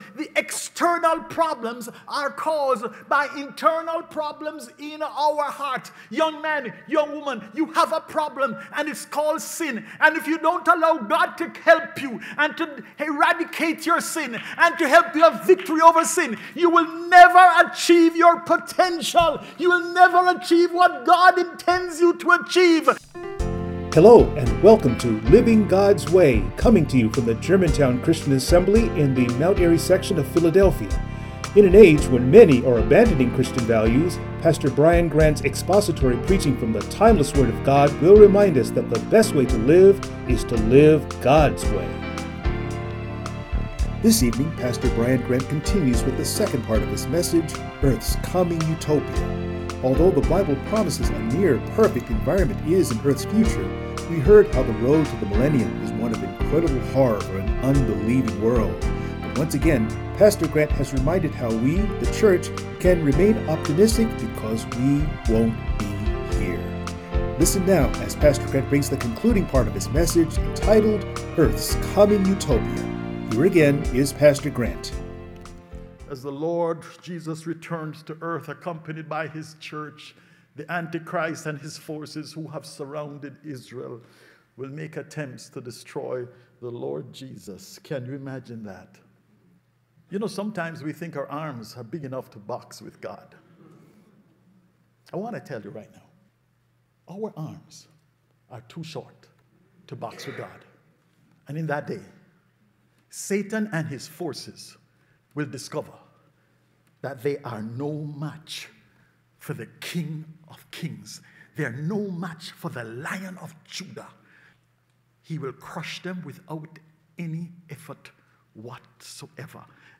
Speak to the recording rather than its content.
Passage: Revelation 19:11-21 Service Type: Sunday Morning